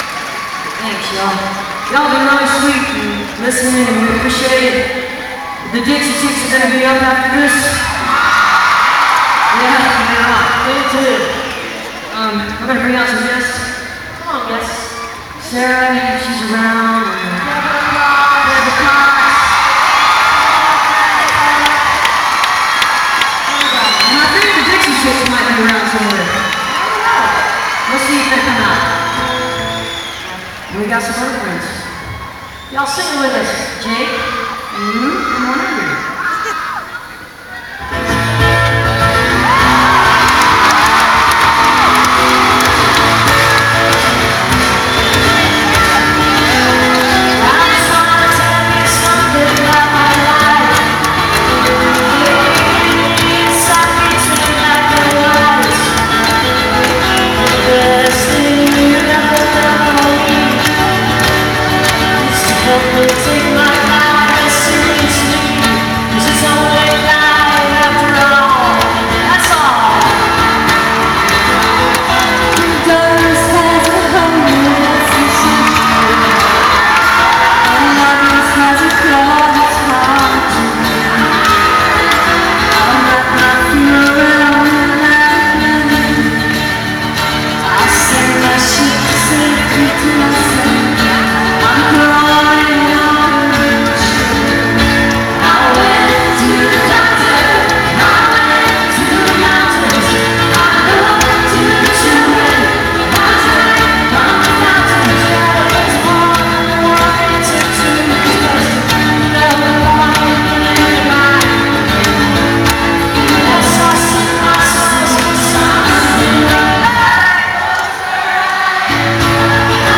(band show)
(has a few skips)